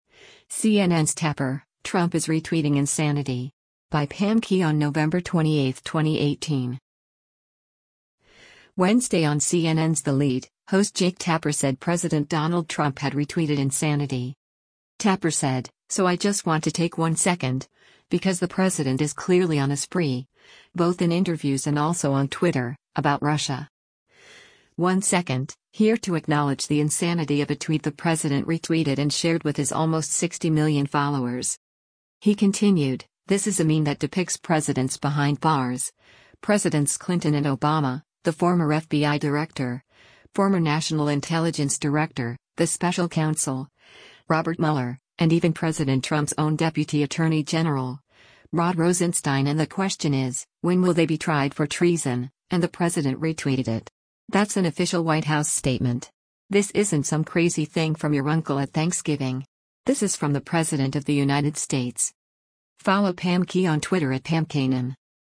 Wednesday on CNN’s “The Lead,” host Jake Tapper said President Donald Trump had retweeted “insanity.”